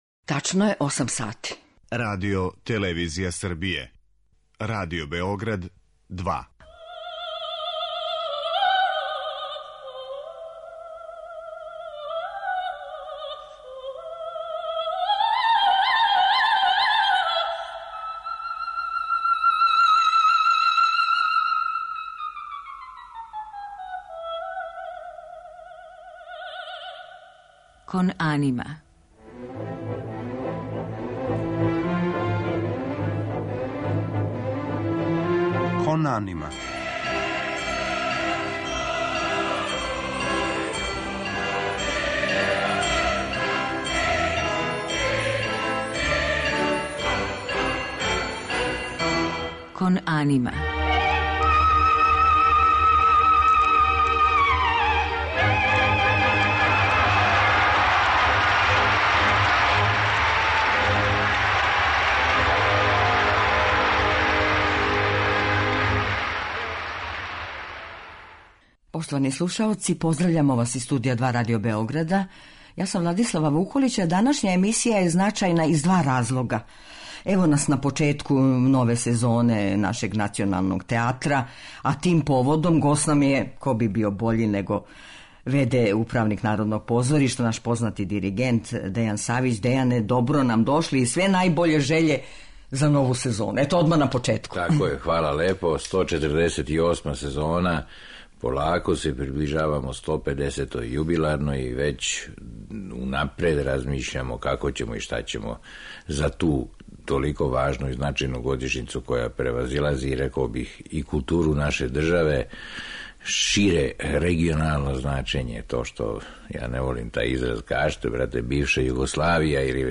В. д. управника Народног позоришта, диригент Дејан Савић, говориће о почетку нове сезоне у нашем националном театру.
У музичком делу биће емитоване арије и сцене у извођењу наших еминентних оперских извођача.